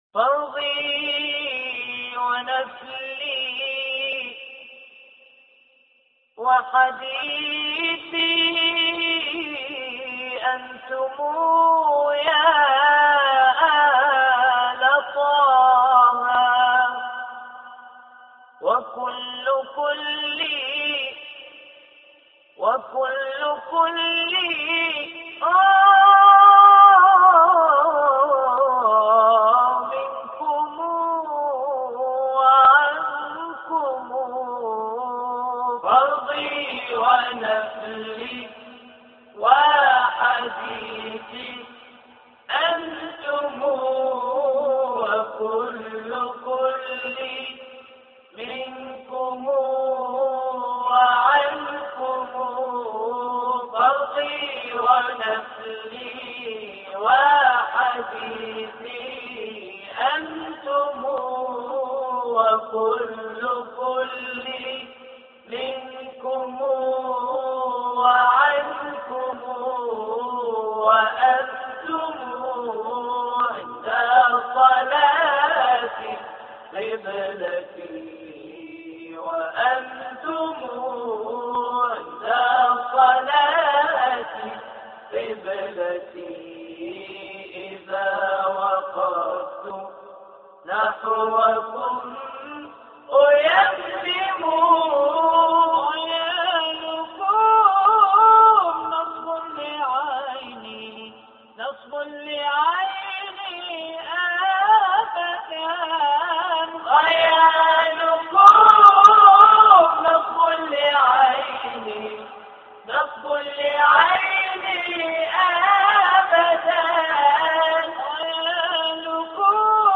پایگاه داده های قرآنی اسلامی تواشیح و مناجات صفحه اصلی